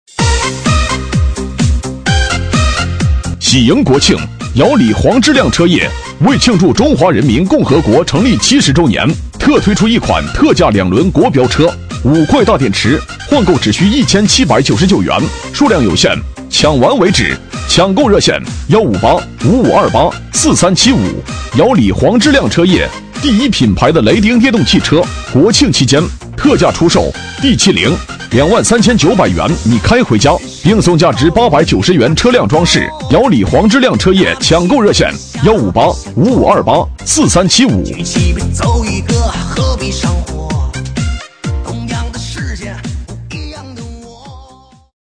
B类男05 | 聆听我的声音
【男5号促销】姚李黄志亮车业.mp3